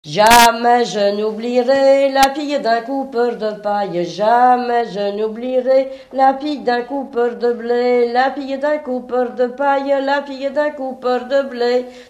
Mémoires et Patrimoines vivants - RaddO est une base de données d'archives iconographiques et sonores.
Couplets à danser
Pièce musicale inédite